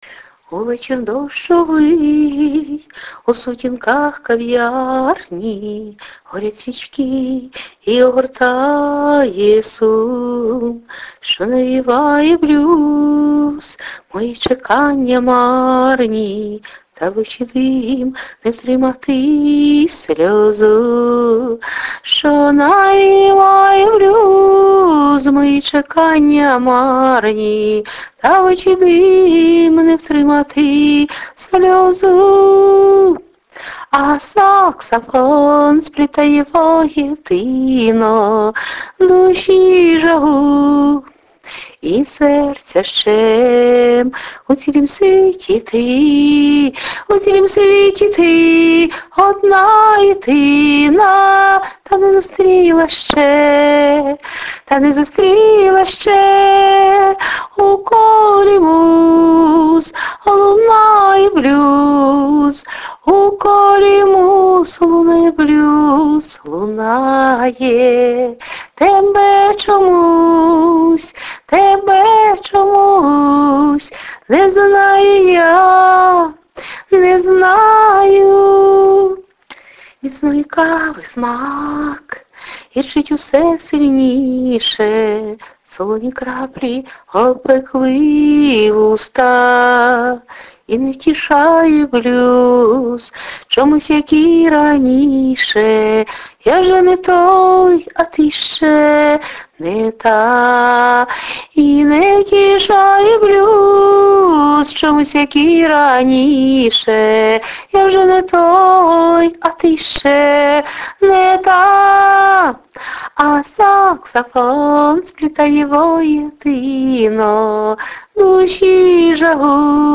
да, детка, это - сакс!
Мелодія вийшла і співаєте гарно)
Техніка ніяка...переспіваю.